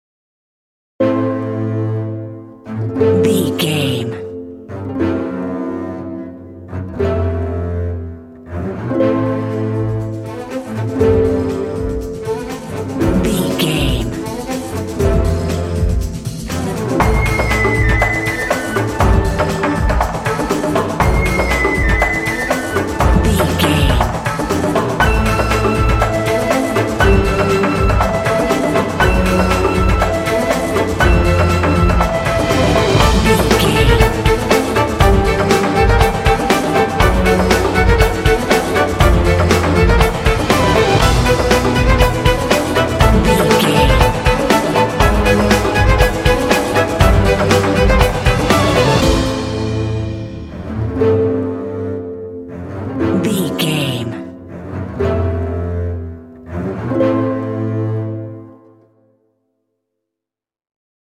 Epic / Action
Aeolian/Minor
Fast
intense
drums
flute
strings
harp
contemporary underscore